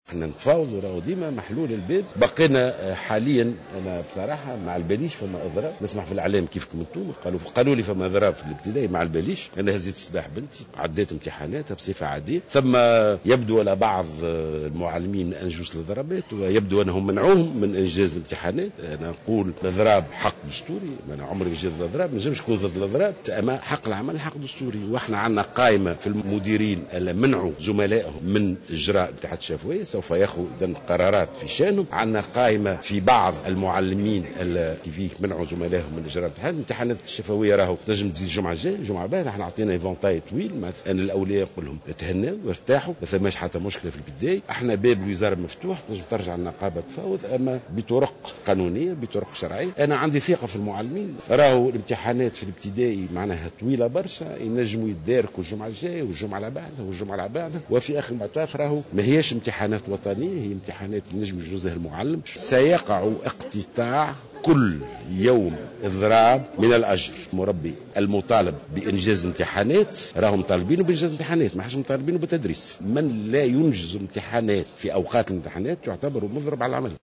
وأضاف الوزير خلال جلسة الاستماع للحكومة بمجلس نواب الشعب أن الوزارة لديها قائمة بأسماء المضربين و مديرين المدارس الذين منعوا المعلمين من إجراء الامتحانات،وفق تعبيره.